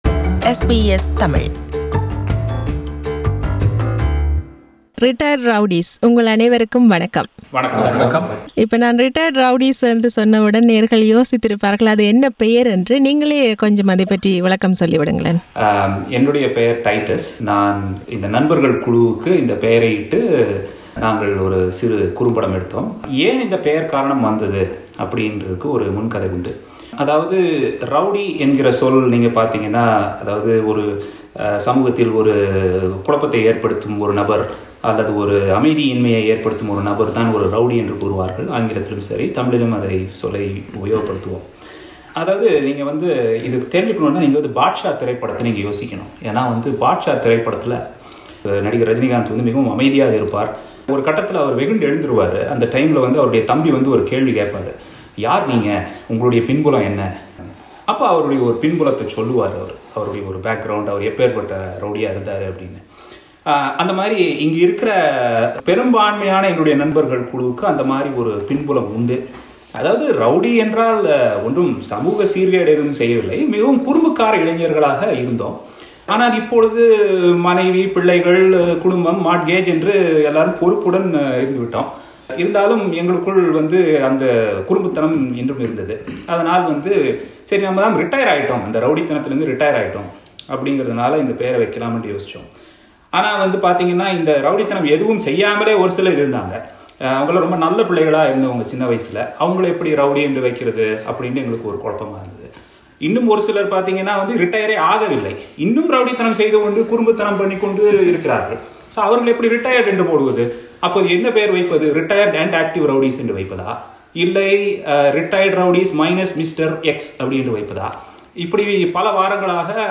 This is an interview with the cast and crew of a tamil movie produced by Vinaithogi Productions in Adelaide.